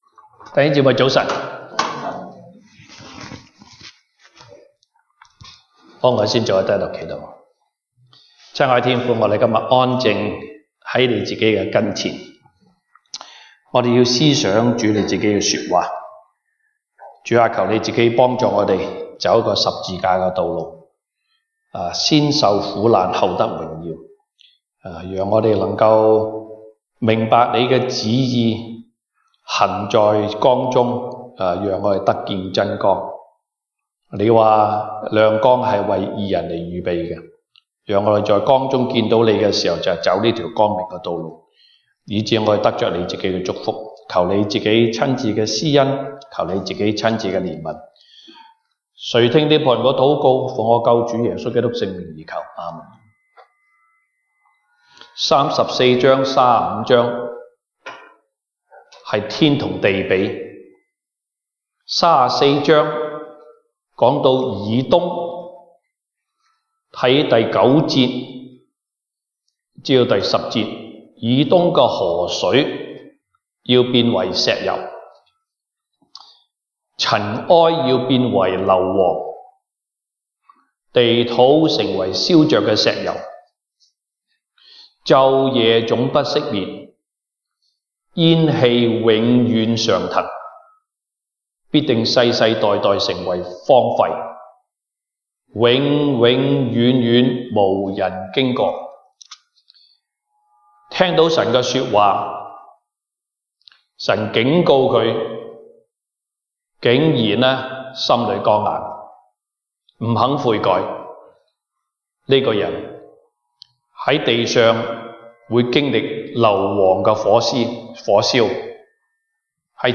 Passage: 以賽亞書 Isaiah 35:1-10 Service Type: 東北堂證道 (粵語) North Side (First Church) Topics